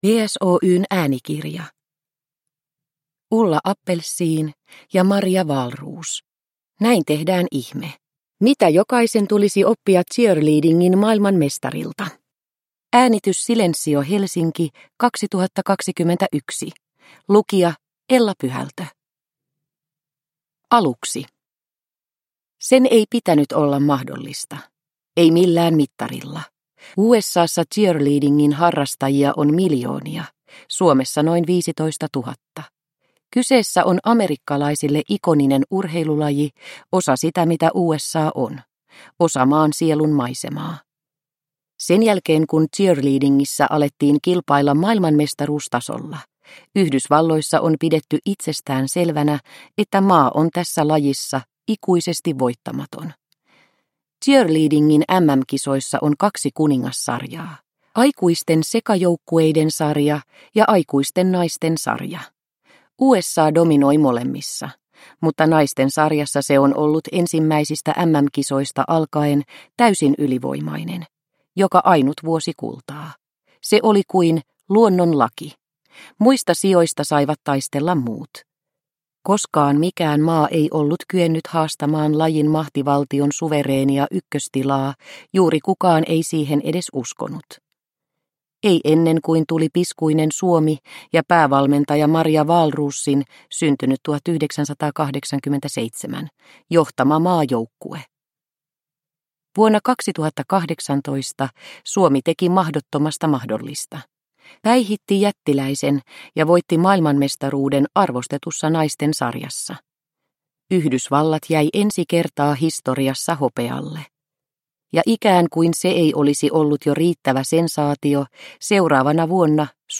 Näin tehdään ihme – Ljudbok – Laddas ner